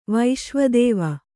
♪ vaiśva dēva